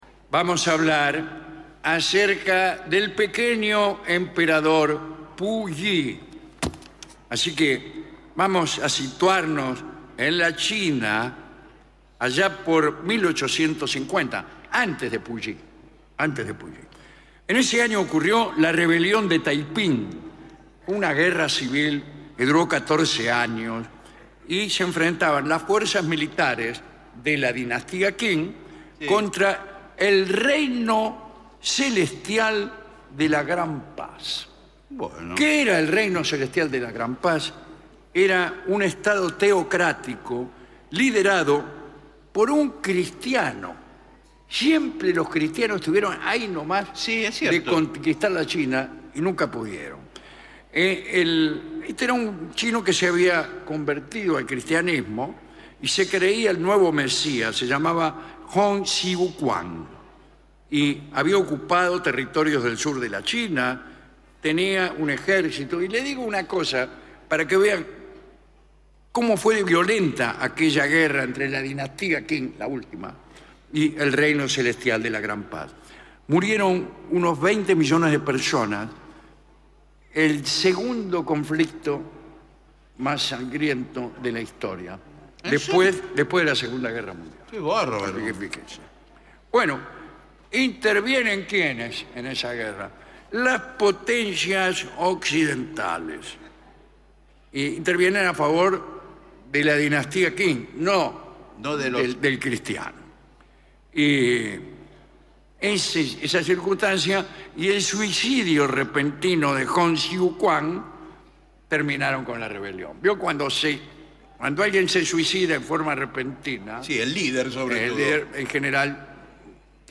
Un clásico de la radio rioplatense con la conducción de Alejandro Dolina